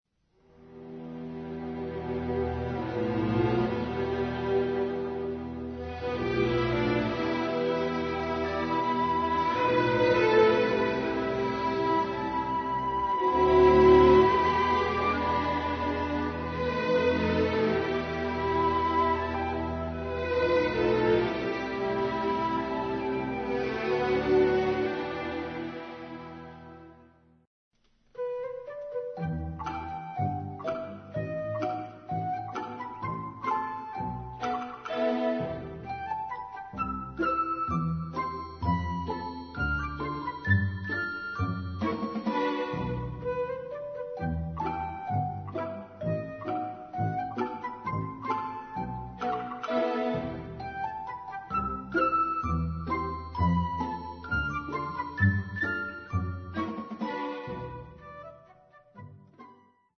Filmmusik
Besetzung: 101(con 1basso)0-0000-batt (2esec) cel ar-archi
- Grazioso, tempo di gavotta - 2'20''